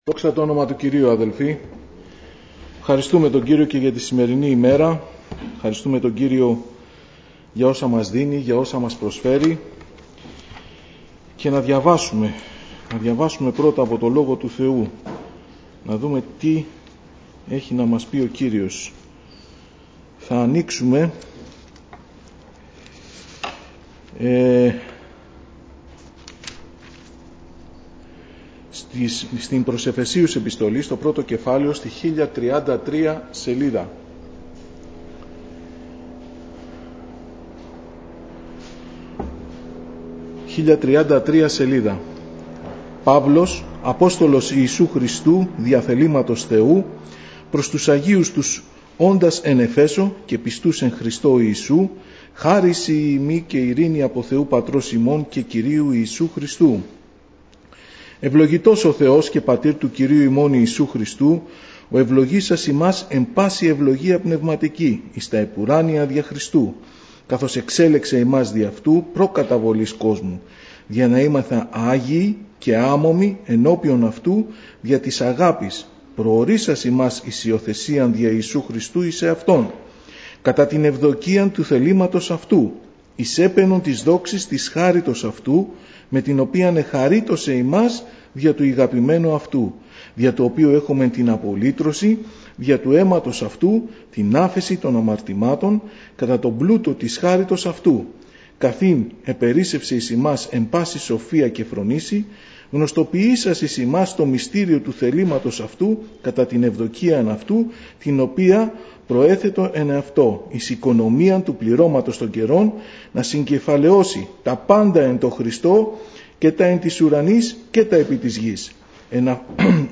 Διάφοροι Ομιλητές απο την Εκκλησία Ομιλητής: Διάφοροι Ομιλητές Λεπτομέρειες Σειρά: Κηρύγματα Ημερομηνία: Παρασκευή, 29 Ιανουαρίου 2021 Εμφανίσεις: 252 Γραφή: Προς Εφεσίους 1 Λήψη ήχου Λήψη βίντεο